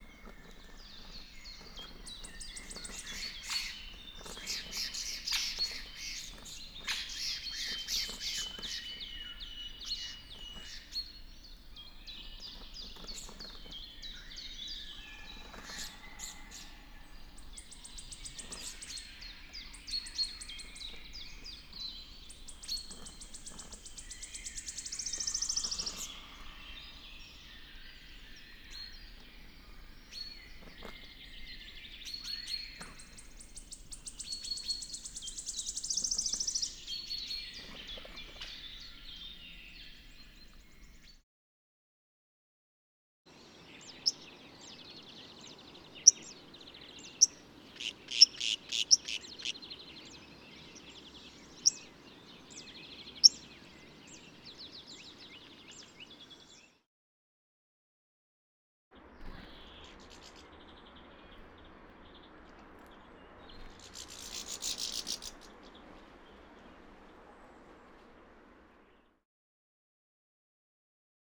The goldfinches deliver the calls with the same noisy use of a broad range of frequencies, but with a different more rattling rhythm.
1-80-Threat-Calls.wav